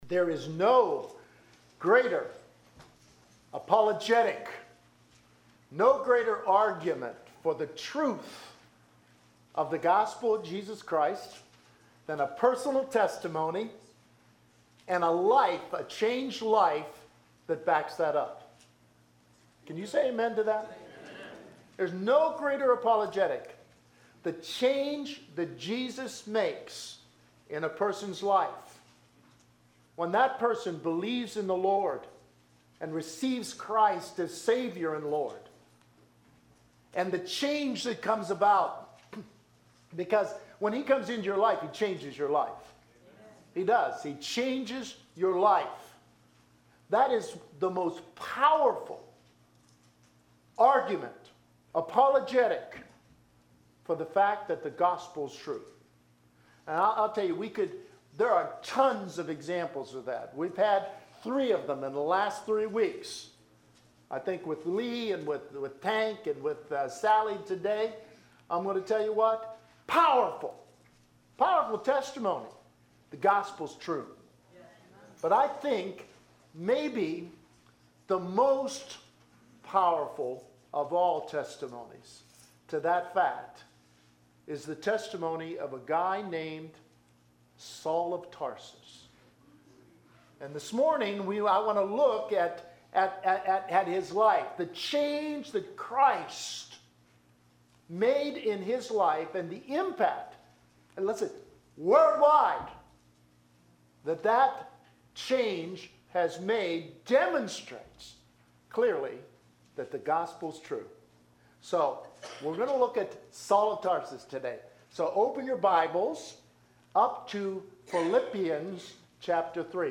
Faith Bible Fellowship Church - Tallmadge, Ohio Sermons: The Power of Personal Testimony Series